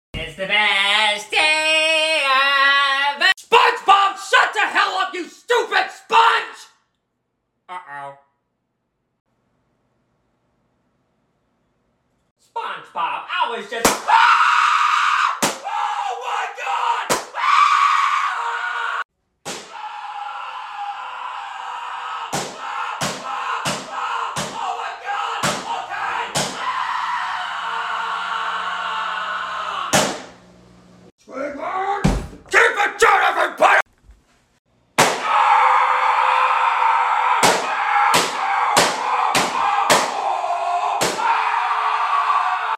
Sounds like something arguing. sound effects free download